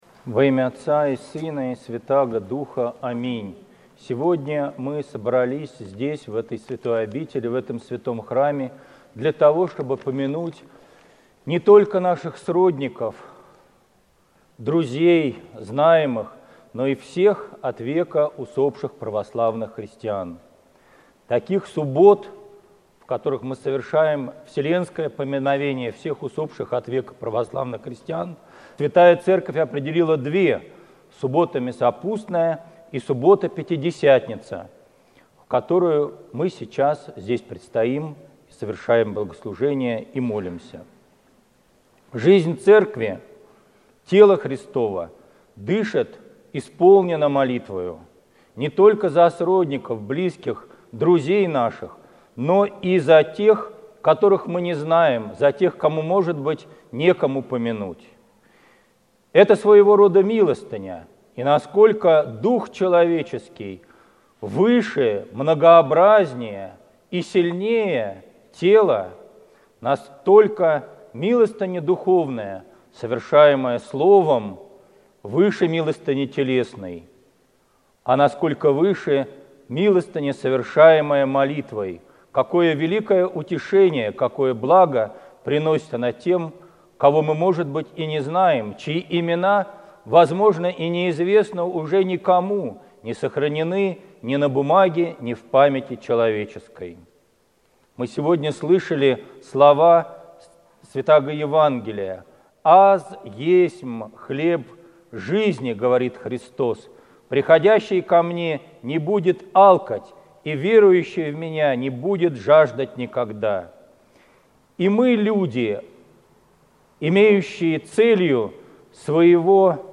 Слово в Троицкую родительскую субботу